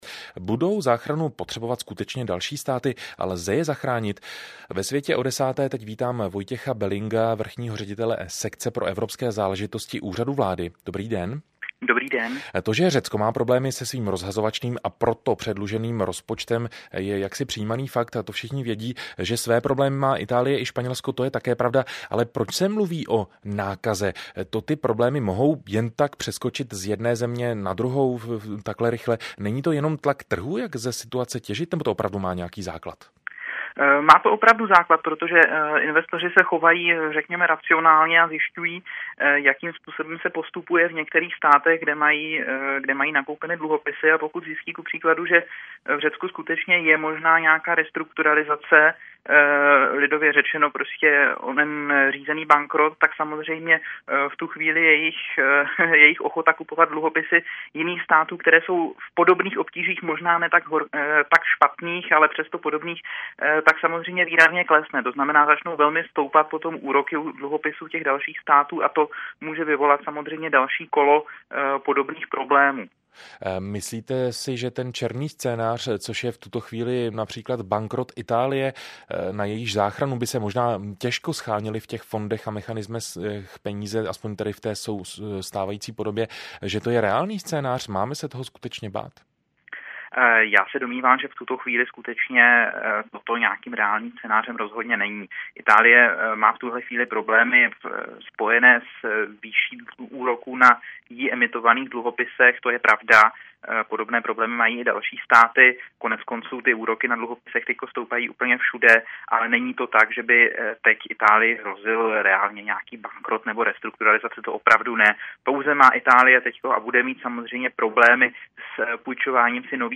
Vrchní ředitel Sekce pro evropské záležitosti na Úřadu vlády Vojtěch Belling mluvil na Rádiu Česko o krizi v eurozóně